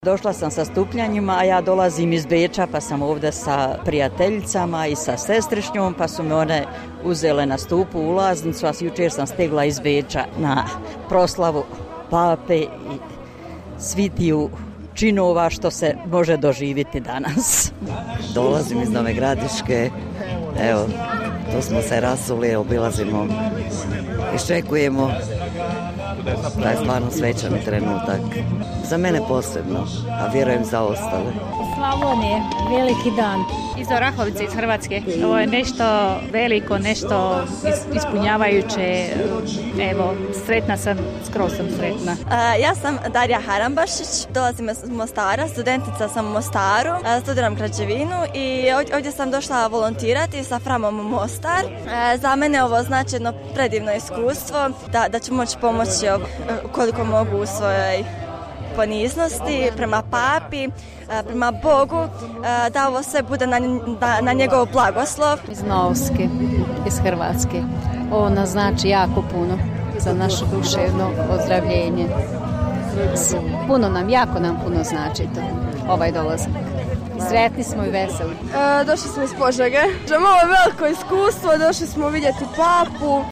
Audio anketa -- Vjernici sa mise za RSE govore o značaju Papine posjete Bosni i Hercegovini:
Sarajevo: Vjernici na misi na Koševu